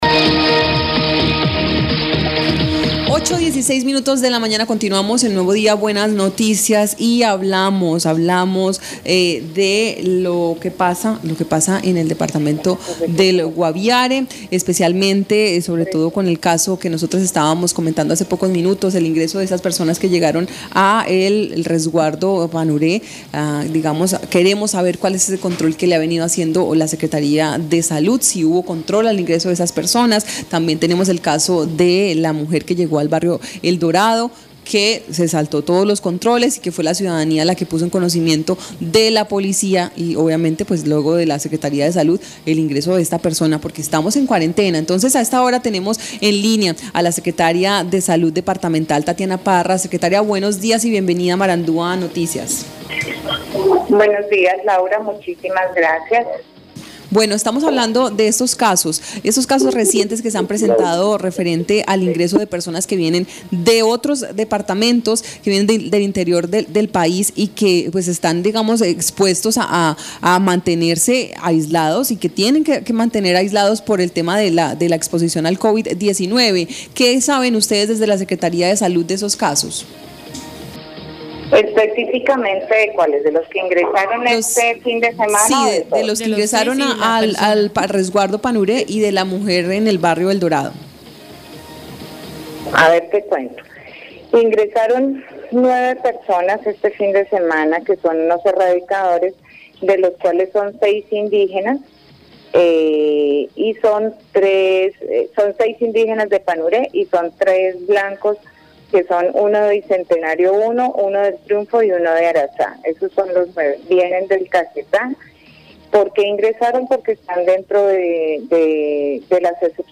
Escuche a Tatiana Parra, secretaria de Salud del Guaviare.